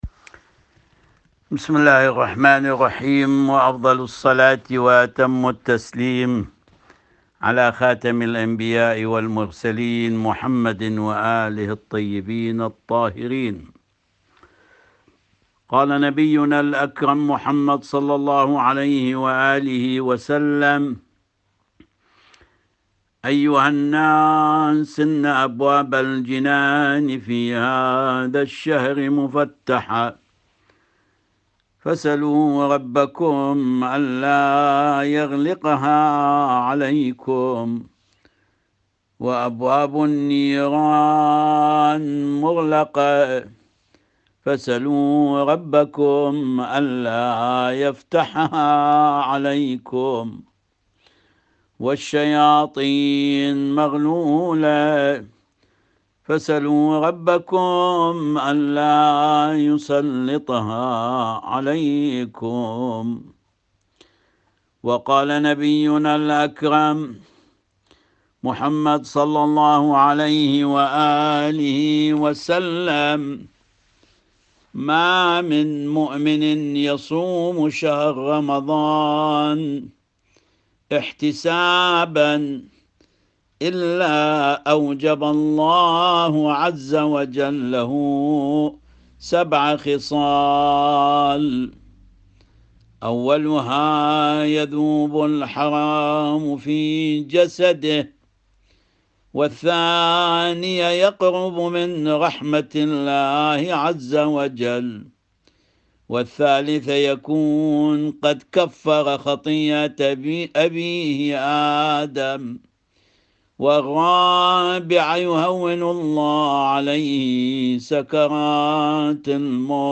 A speech